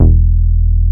R MOOG E2MP.wav